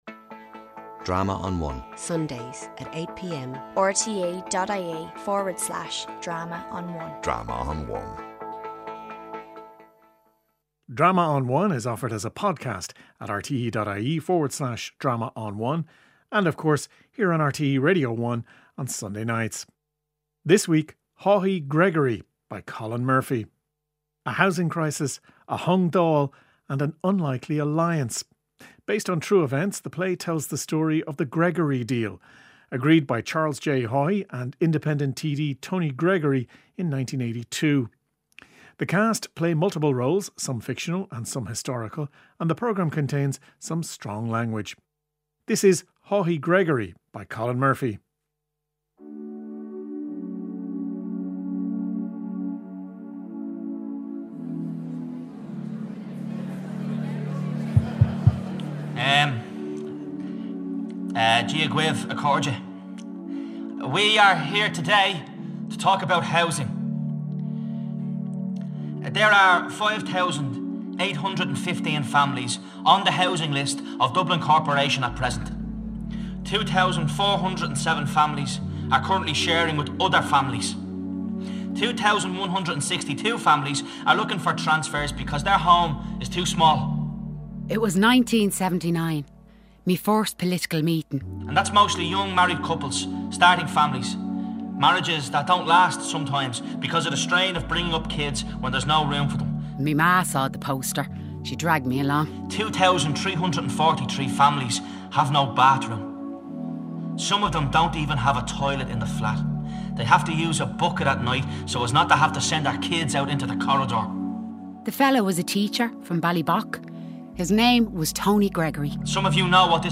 Audio Theater
Audio Drama